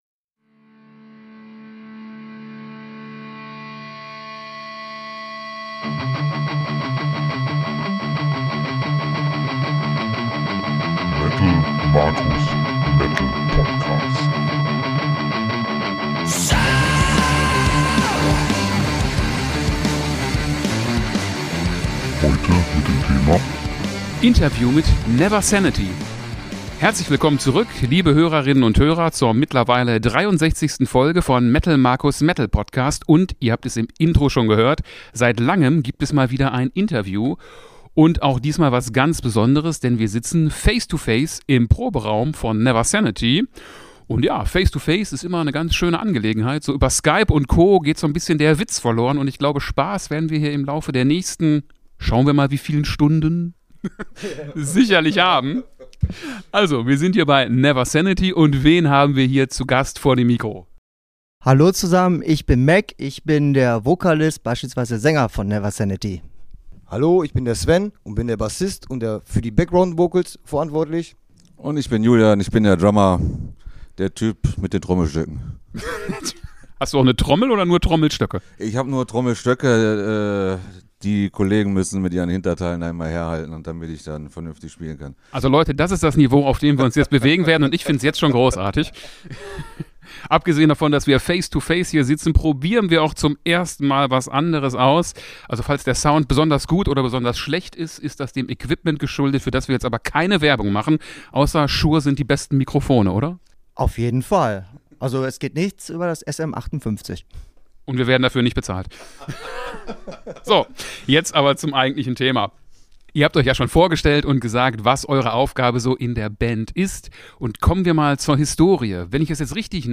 Folge 63 - Interview mit NeverSanity